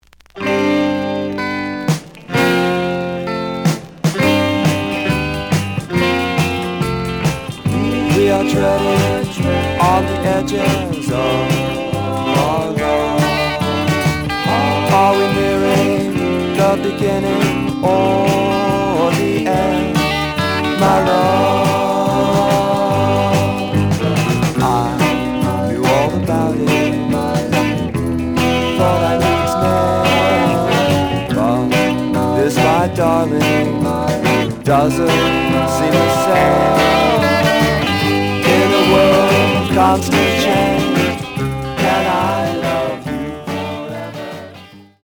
The audio sample is recorded from the actual item.
●Genre: Rock / Pop
B side plays good.)